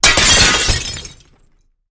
Звуки зеркала
На этой странице собраны звуки, связанные с зеркалами: от едва уловимых отражений до резких ударов.